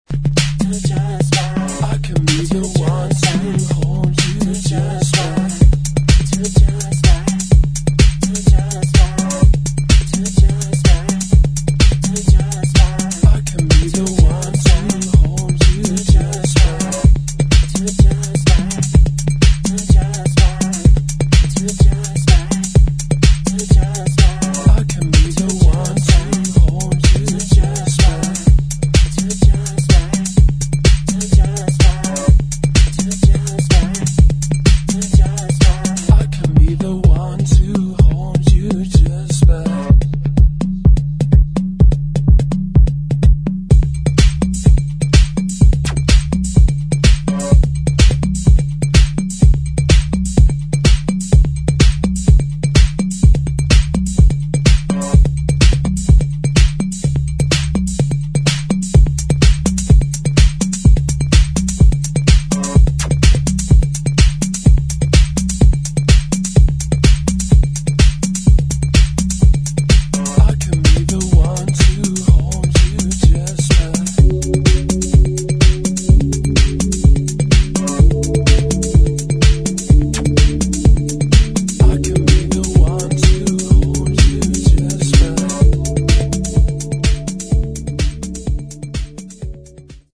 [ DEEP HOUSE / DISCO ]